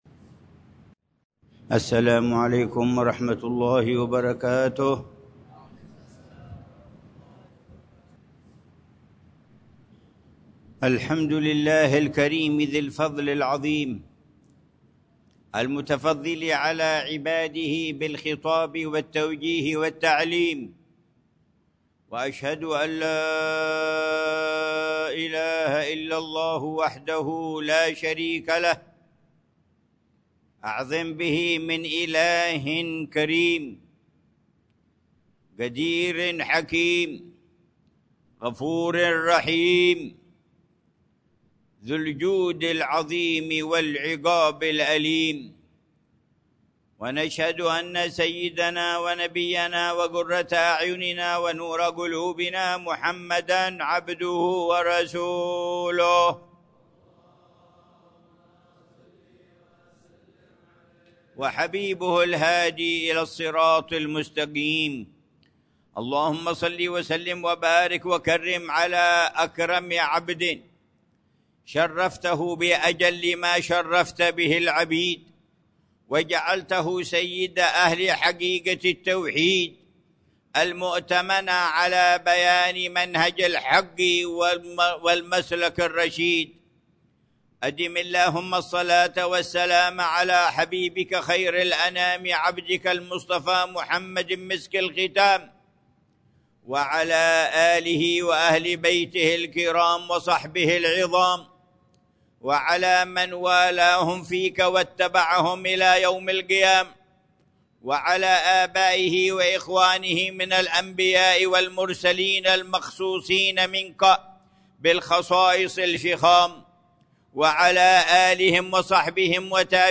خطبة الجمعة للعلامة الحبيب عمر بن محمد بن حفيظ في جامع الإيمان، بحارة الإيمان، عيديد، مدينة تريم، 9 جمادى الأولى 1447هـ بعنوان: